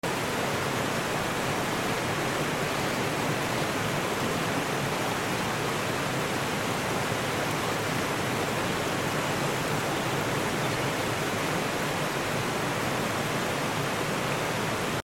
Rising Up Over Mossy Cascades Sound Effects Free Download